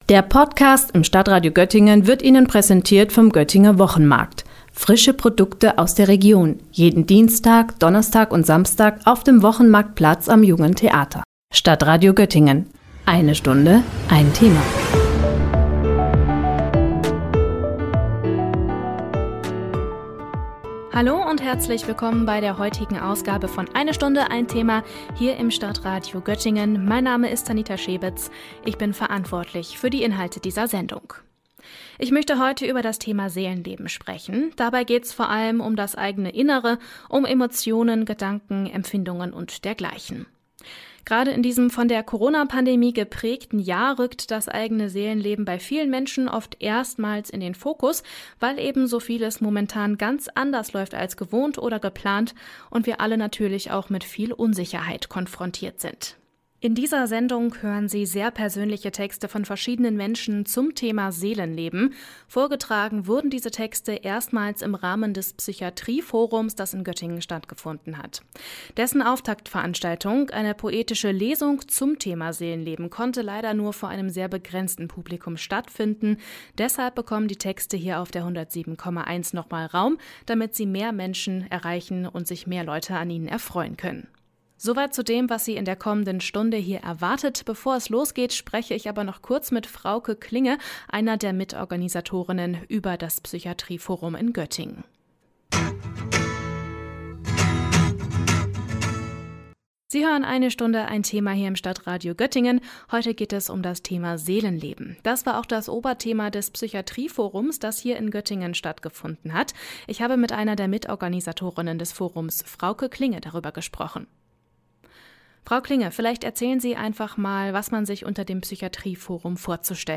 Seelenleben – Texte der poetischen Lesung des Psychiatrie-Forums Göttingen